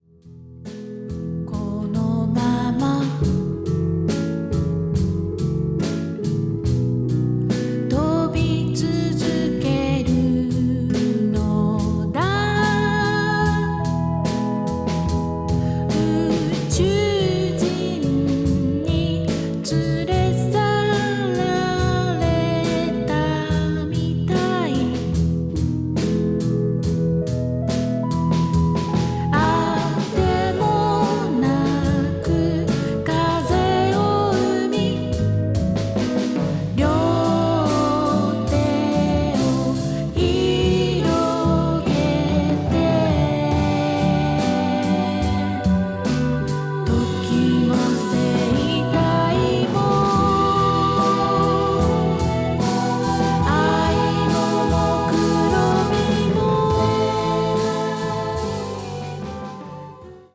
幻想の中に真理がある、童話のような歌たち。
サイケデリックであるけれど、アングラ過ぎない。